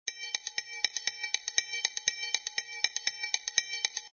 shared_triangle00.mp3